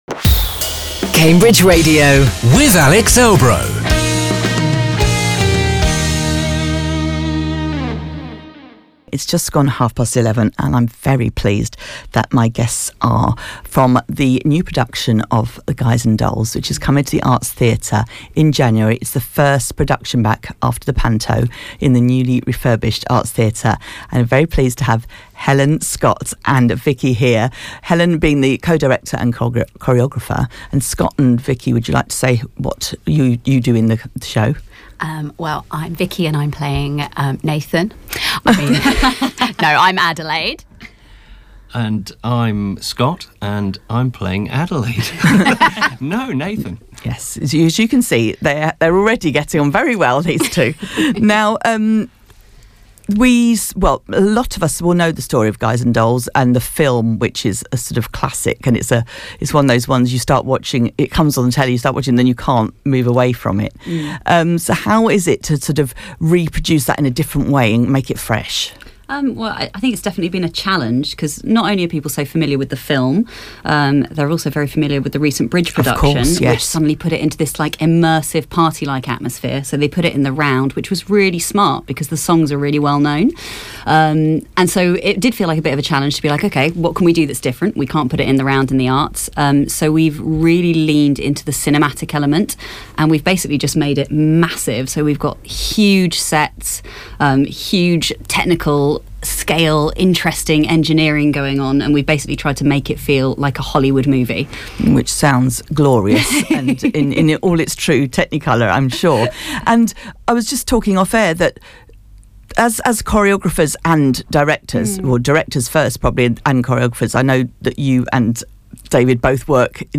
The cast of the new production of Guys and Dolls talk